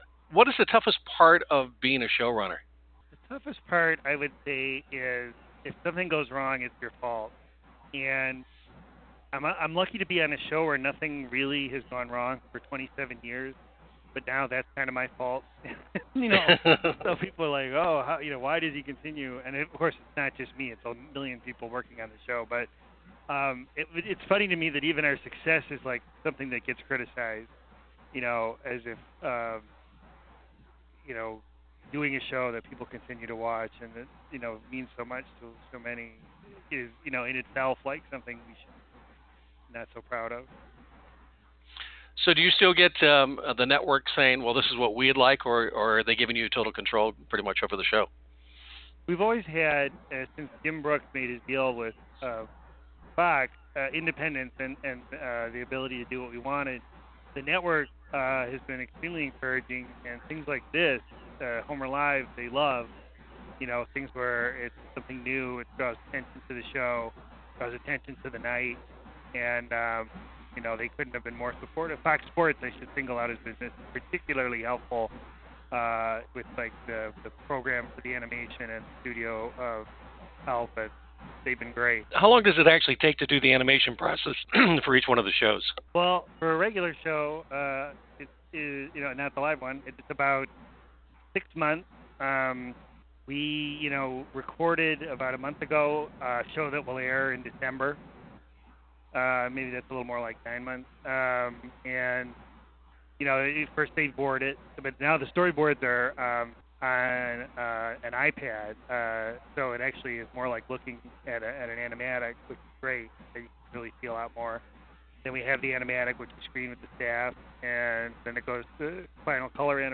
Interview With Al Jean Producer of The Simpsons
Entertainment World interviews Showrunner and Executive Producer of the Simpsons.